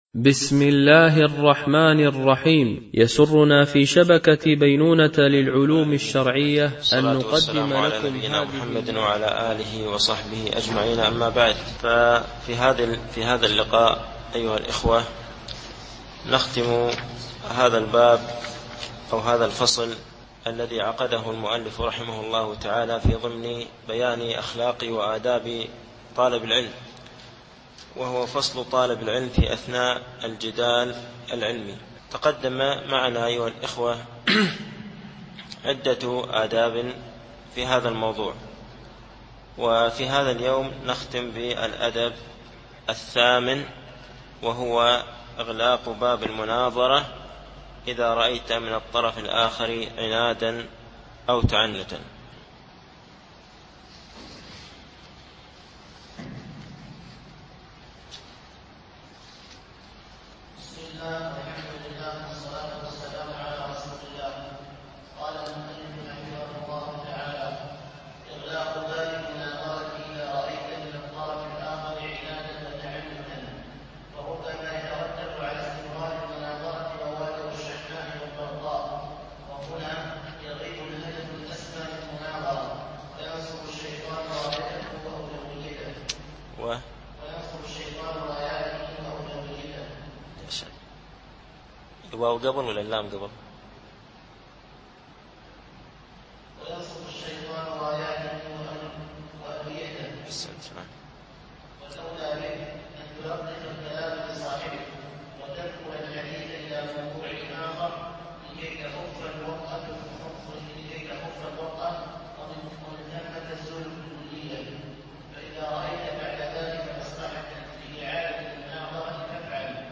- الدرس السادس و الثلاثون الألبوم: شبكة بينونة للعلوم الشرعية التتبع: 36 المدة: 28:16 دقائق (6.51 م.بايت) التنسيق: MP3 Mono 22kHz 32Kbps (CBR)